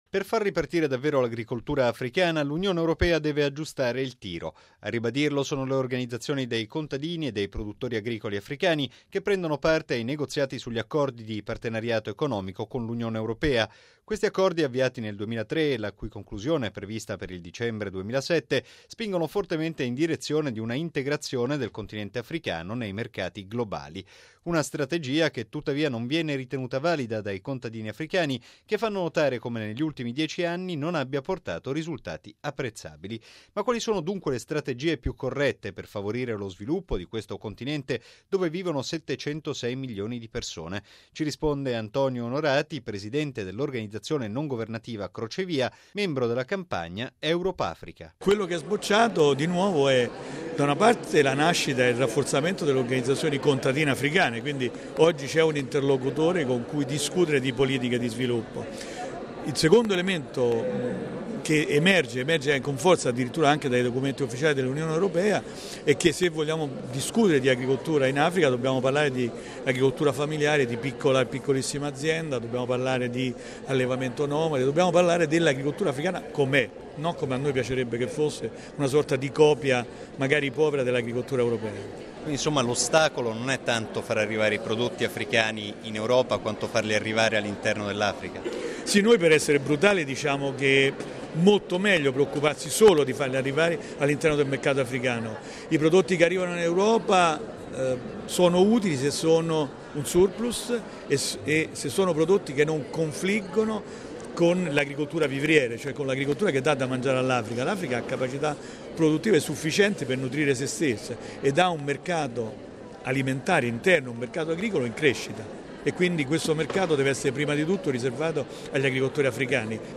Questo il tema sul quale si dibatte oggi in Campidoglio, a Roma, nell’ambito di un seminario proposto dalla campagna Europa – Africa.